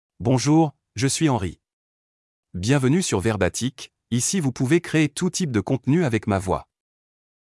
MaleFrench (France)
Voice sample
Male
French (France)